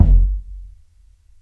KICK SOFT.wav